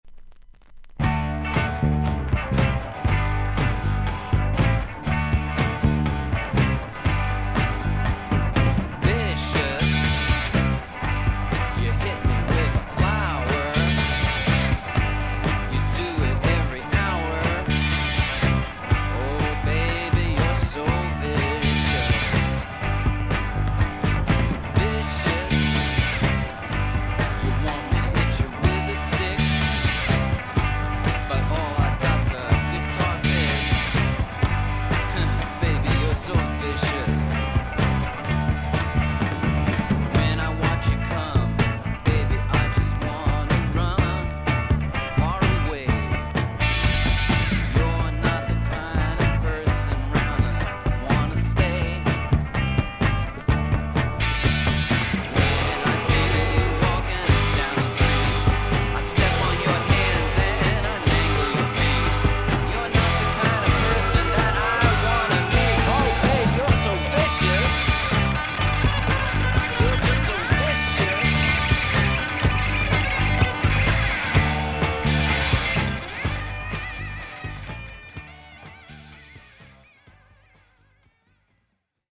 少々軽いパチノイズの箇所あり。少々サーフィス・ノイズあり。クリアな音です。